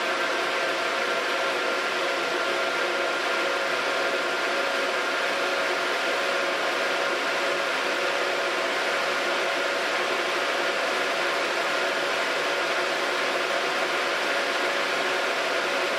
Again, 630 Hz is high, and the adjustment frequencies to 1.6 KHz are also high.
I have recorded the signals shown above, but please keep in mind that I’ve enabled Automatic Gain Control (AGC) to do so to make it easier for you to reproduce them.
90% Fan Speed